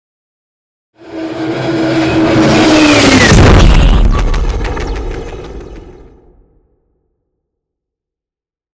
Scifi passby whoosh long
Sound Effects
futuristic
pass by